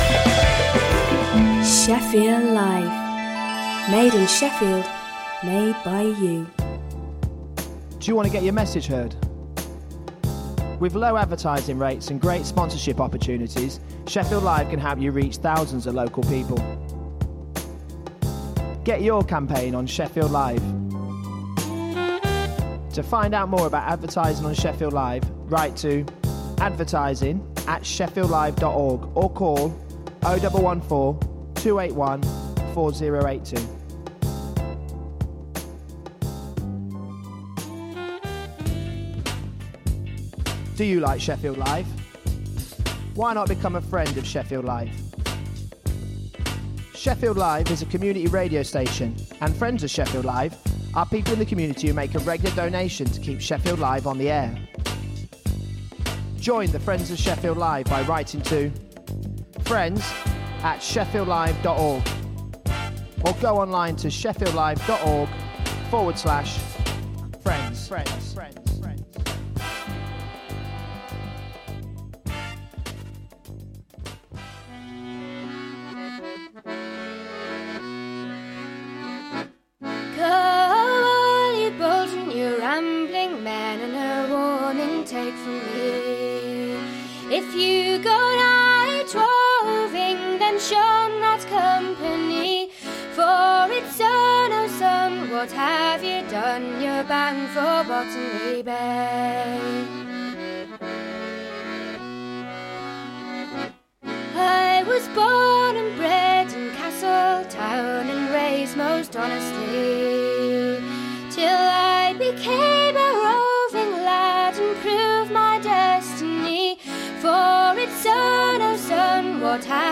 Deserted Ireland Discs – Stories & Songs from the Irish in Sheffield Deserted Ireland Discs brings the Irish experience in Sheffield to life through music and storytelling. Each week, Irish people who now live in Sheffield share their journeys from Ireland to South Yorkshire, choosing songs that have shaped their lives.